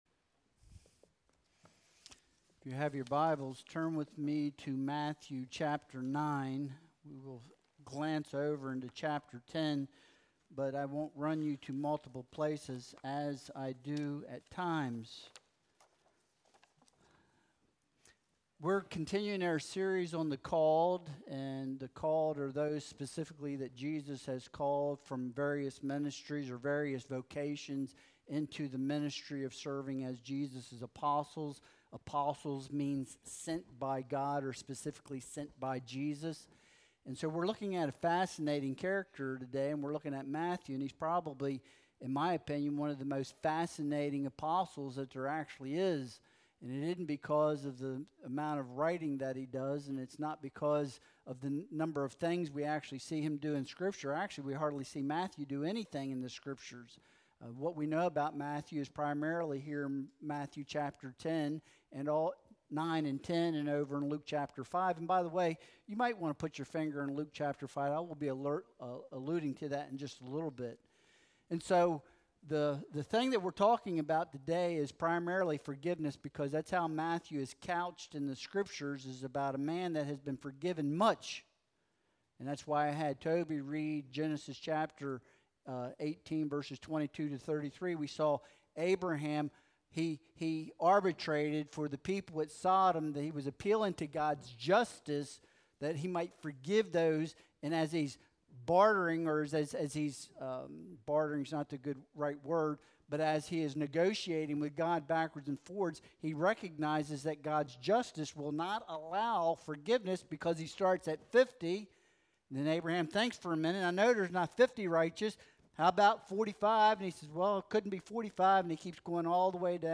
Matthew 9.9-13 Service Type: Sunday Worship Service « Judas Iscariot